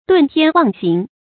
遁天妄行 注音： ㄉㄨㄣˋ ㄊㄧㄢ ㄨㄤˋ ㄒㄧㄥˊ 讀音讀法： 意思解釋： 謂違背自然規律而胡作非為。